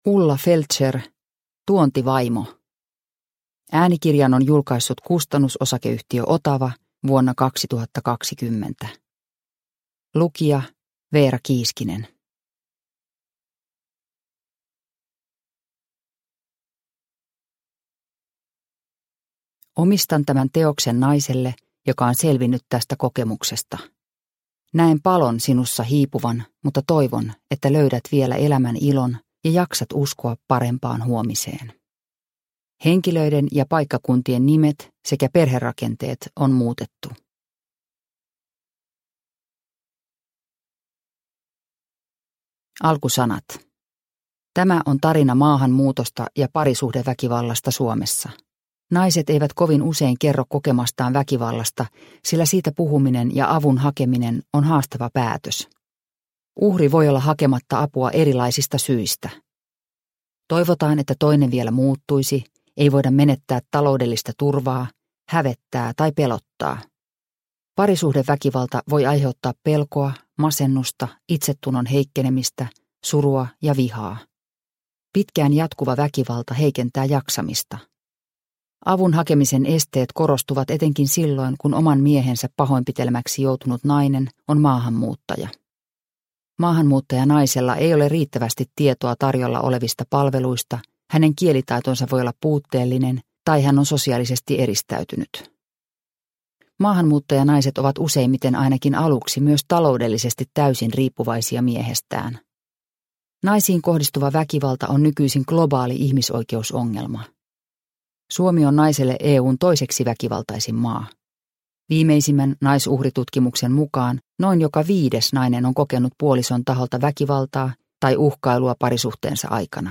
Tuontivaimo – Ljudbok – Laddas ner